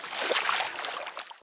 water-04.ogg